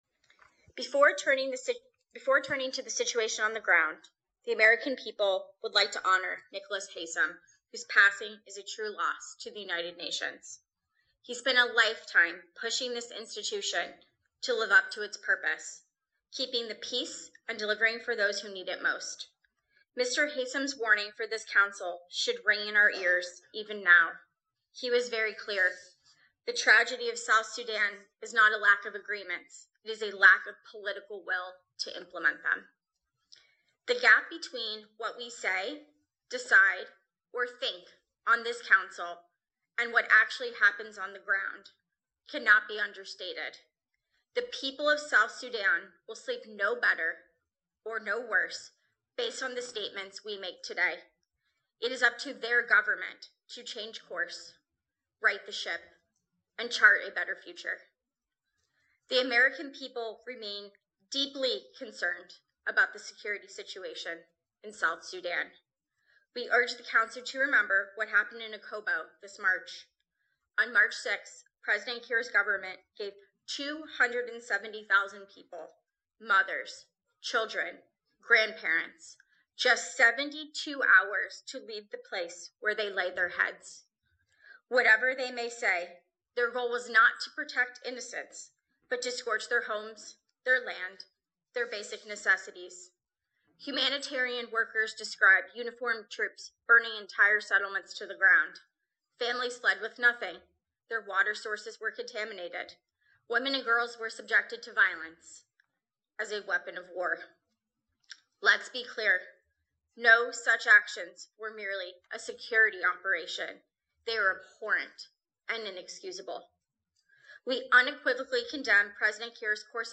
US-ambassador-statement.mp3